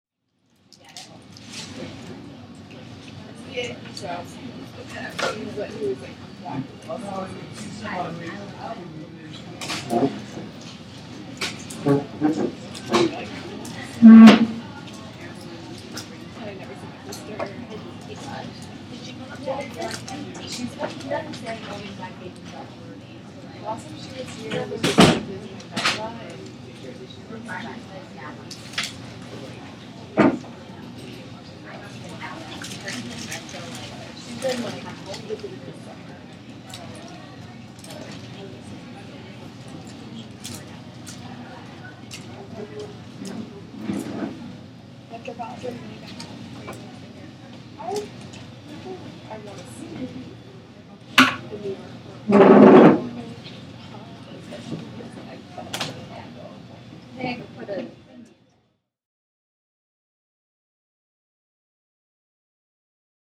chairs scraping the floor – Hofstra Drama 20 – Sound for the Theatre
Field Recording #1
Sounds in Clip : Various levels of talking/chatter from various distances, phone dropping on table, bags being dropped on chair, hollow bottle being dropped on table, clanking and moving of chairs
Location: Hofstra University Starbucks Cafe